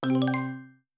yay.mp3